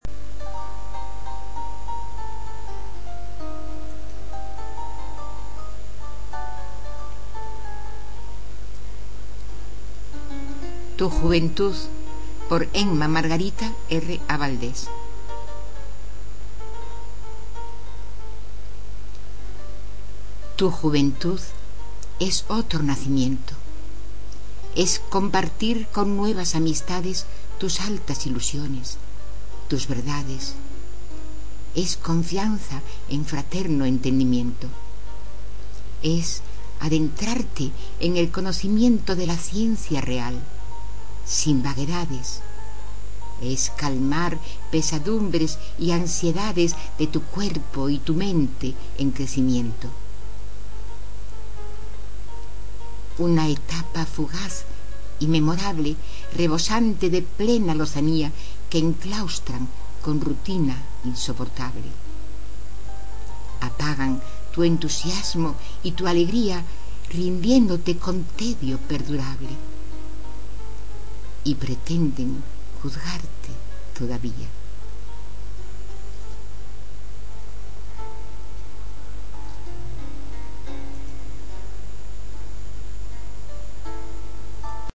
En mp3, recitada por la autora, pulsar